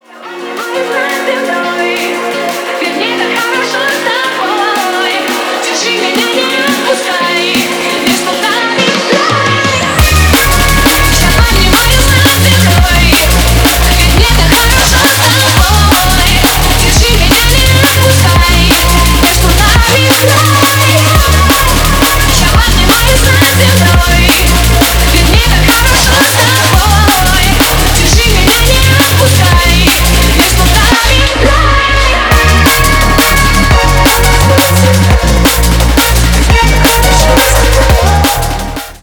Ремикс # Поп Музыка
клубные # громкие